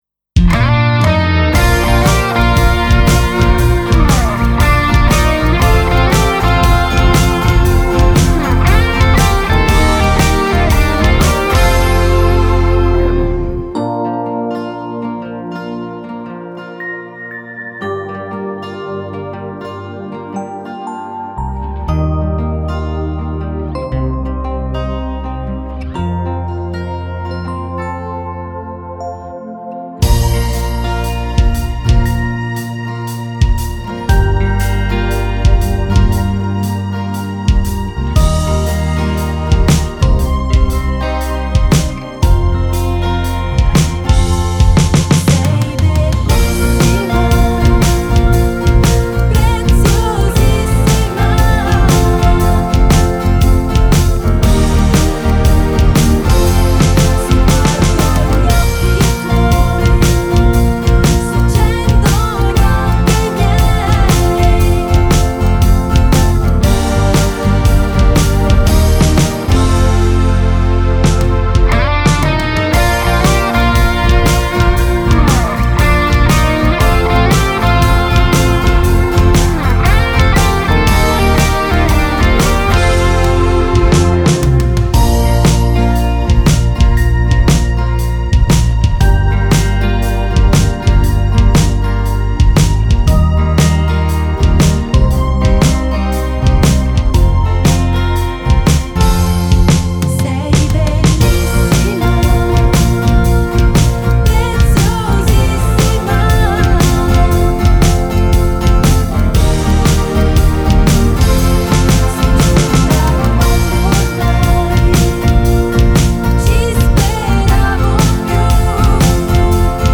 3:28 Scarica il testo Scarica la base per Karaoke